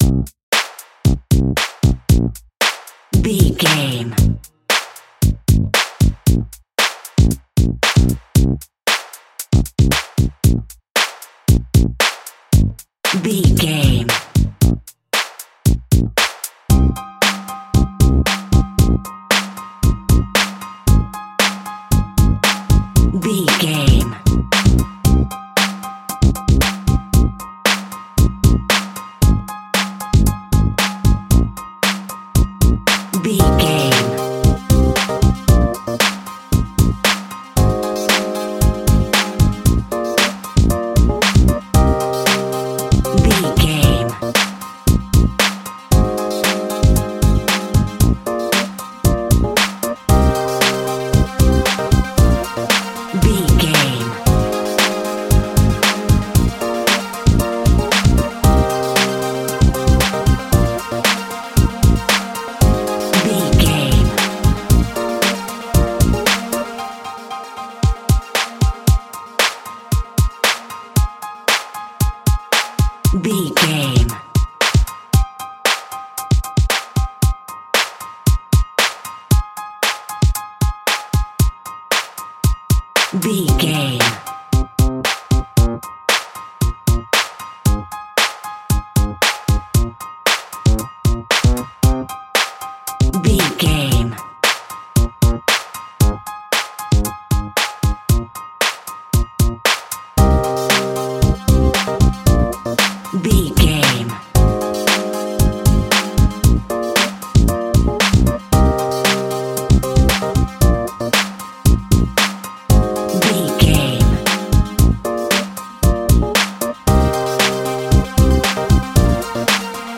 The Only Hip Hop Music Track.
Aeolian/Minor
electric drums
drum machine
Hip Hop Synth Lead
Hip Hop Synth Bass
synths